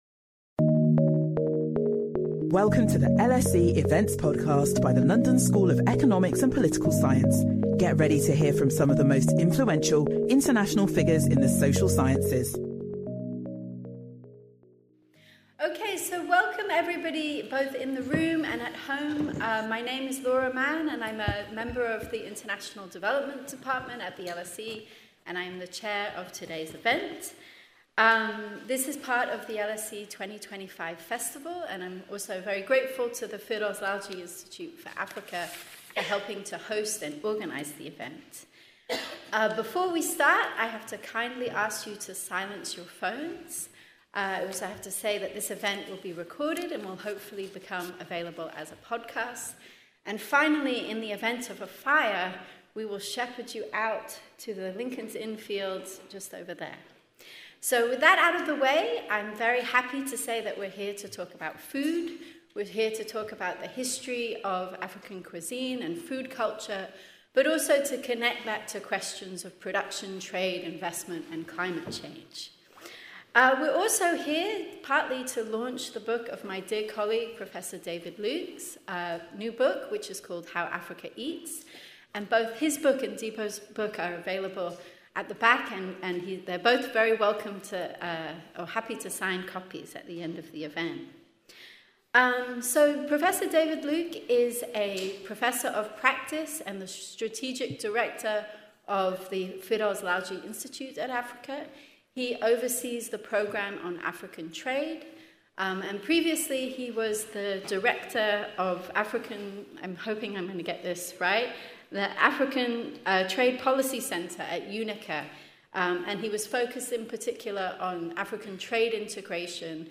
this diverse panel of tastemakers will explore the history of African cuisine; production and distribution, as well as considering the factors which may disrupt these; food security, food trade, and climate risks.